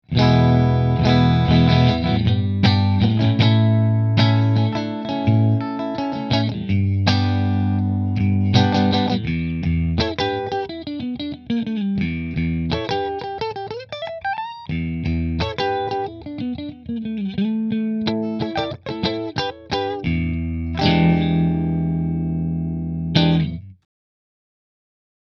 It has the snappiness from the Les Paul Special with it’s P90s, but also has the rounded tone from the semi-hollow design of the Telecaster Deluxe.
• Three Custom Wound P90s
New Orleans Guitars Model-8 Sunburst Middle Middle Through Fender